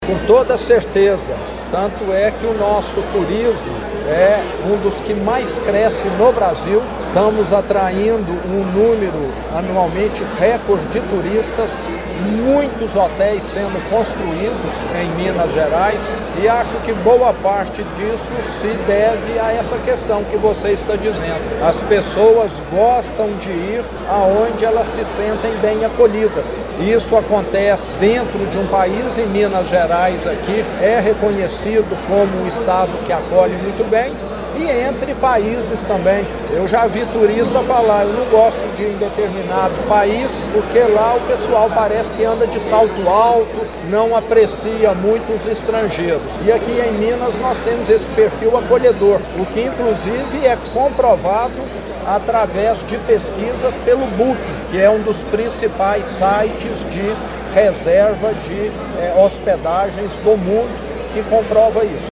O evento ocorreu na quadra poliesportiva da Escola Estadual Nossa Senhora Auxiliadora, no bairro São Cristóvão, com a presença do governador do Estado de Minas Gerais, Romeu Zema, que ressaltou a importância de Pará de Minas para o desporto estudantil.